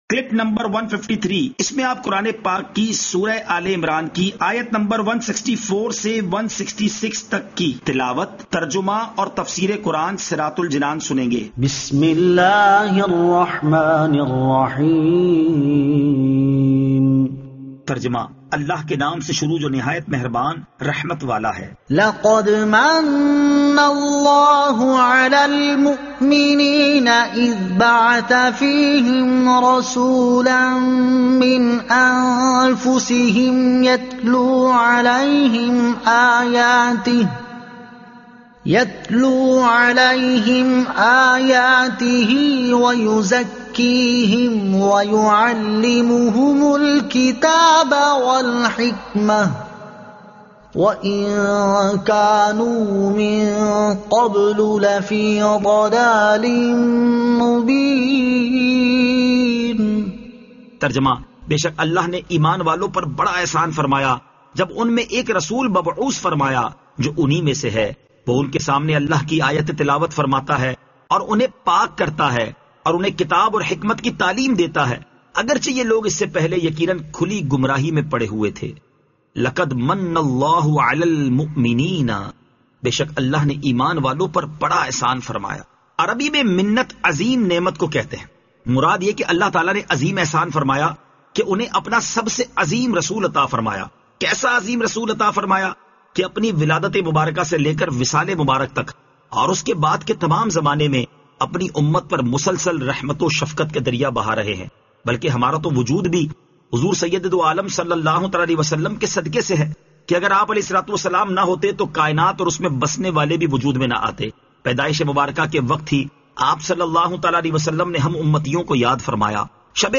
Surah Aal-e-Imran Ayat 164 To 166 Tilawat , Tarjuma , Tafseer